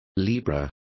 Complete with pronunciation of the translation of libra.